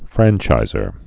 (frănchīzər)